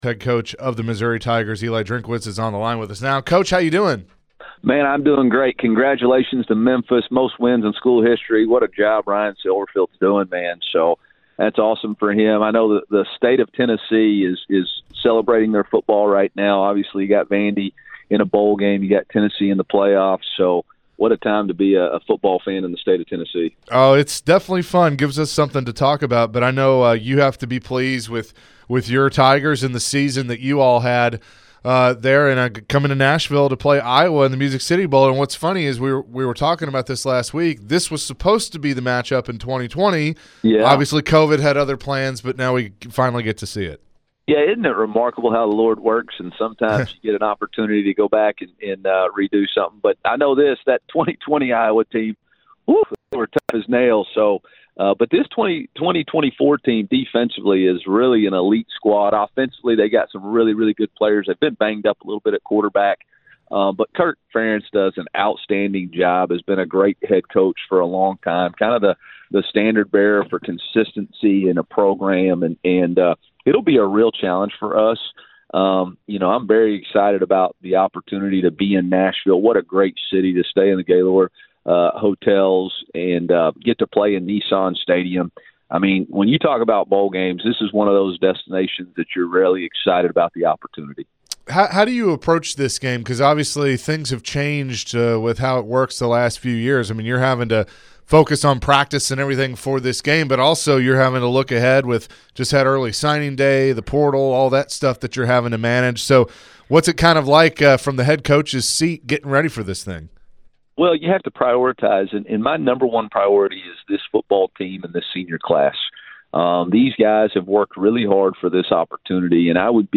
The guys started the show with Missouri Tigers Head Football Coach Eli Drinkwitz. Eli covered all things about his team and the preparation heading into the Music City Bowl.